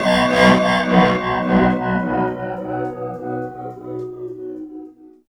18 GUIT 3 -L.wav